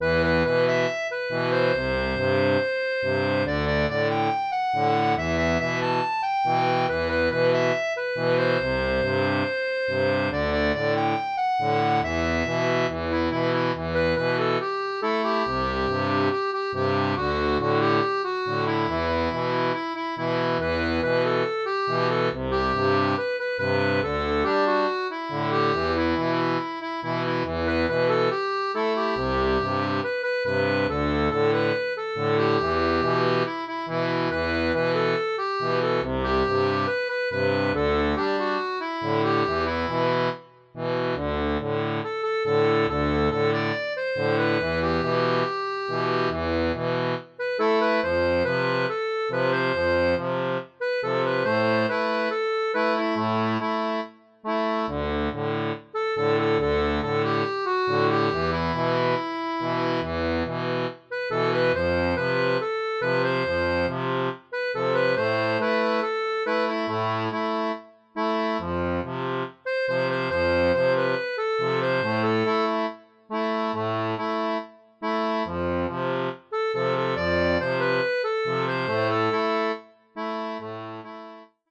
• un fichier audio correspondant à la transposition en Mi
Reggae